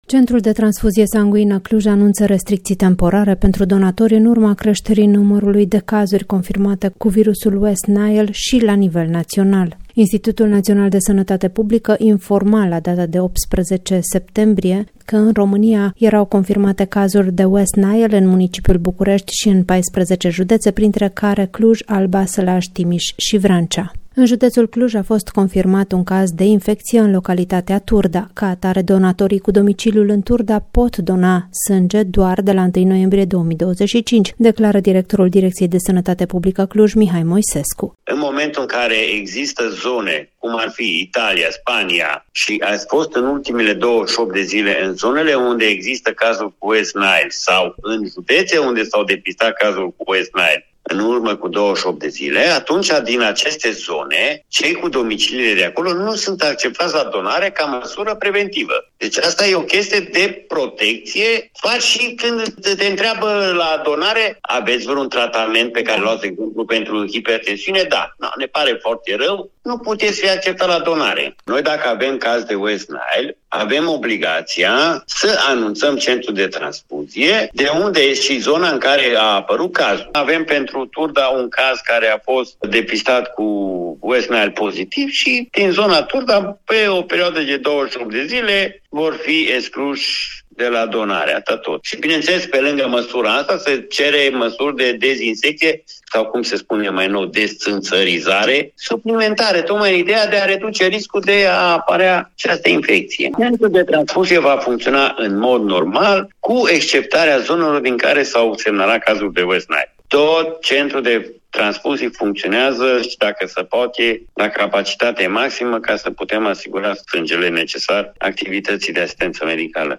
Ca atare, donatorii cu domiciliul în Turda pot dona sânge doar de la 1 noiembrie 2025, declară directorul Direcției de Sănătate Publică Cluj, Mihai Moisescu: